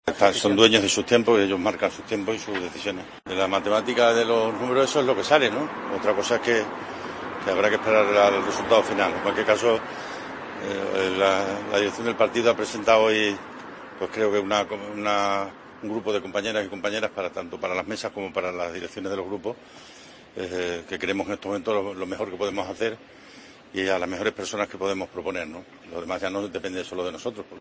Lo ha hecho en declaraciones a los periodistas a las puertas de la sede del PSOE en la madrileña calle Ferraz, donde esta mañana la Ejecutiva Federal de los socialistas ha propuesto la candidatura de Vara a vicepresidente del Senado y la de la expresidenta de Baleares, Francina Armengol, a presidir el Congreso.